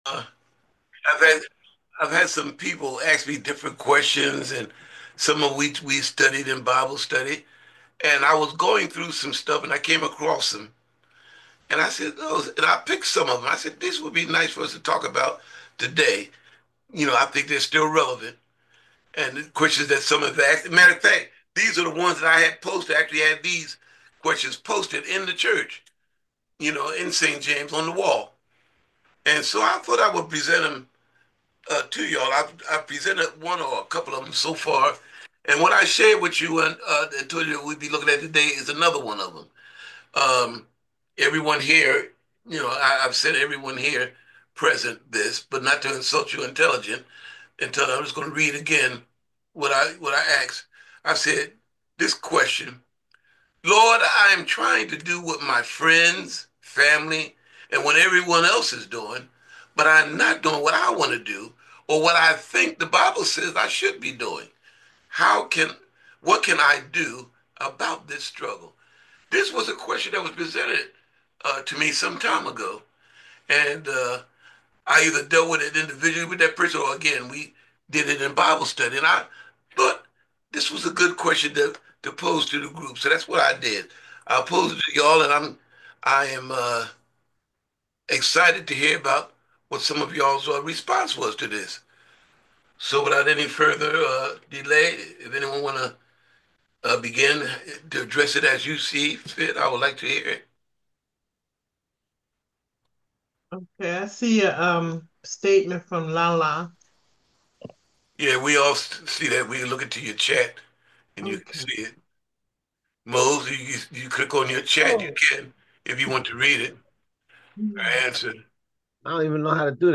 Teach Me: Wednesday Night Bible Study - St James Missionary Baptist Church
bible study